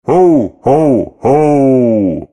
Звуки Санта-Клауса
Звук рождественского деда: Повторение хо-хо-хо